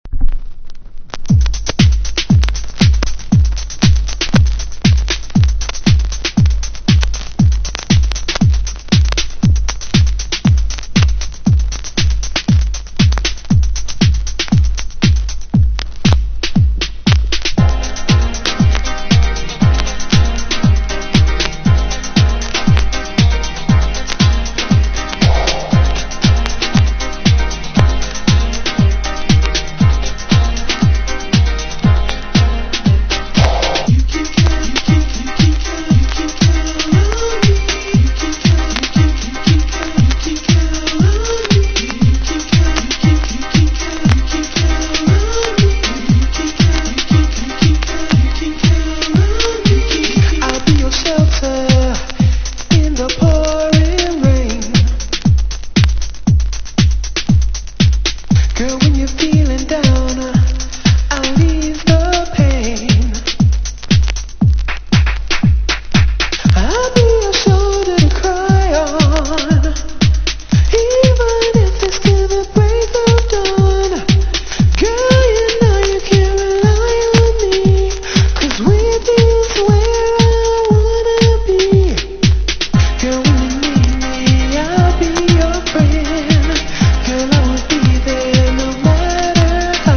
• HOUSE
シカゴ・ハウス名作！